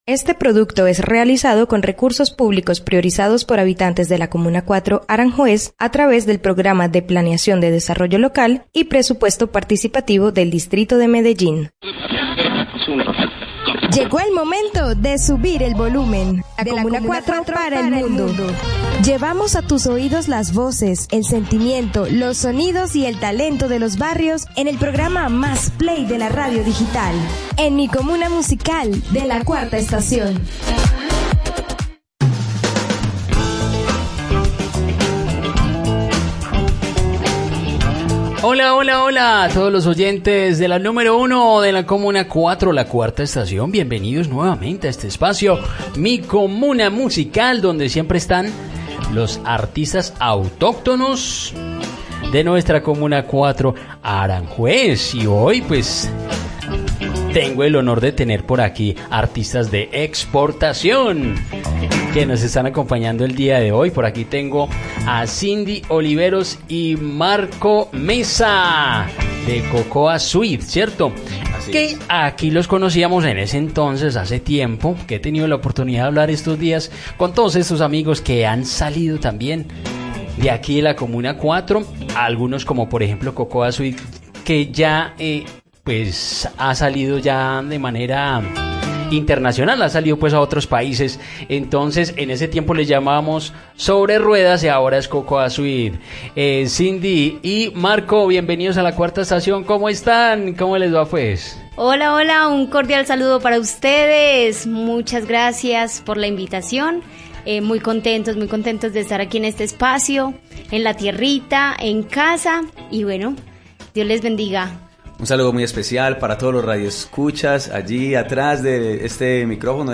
La Agrupación CocoaSweet Band, llego a nuestras instalaciones, para conversar sobre su evolución, el origen de su iniciativa, sus futuros proyectos y su impacto en la escena musical.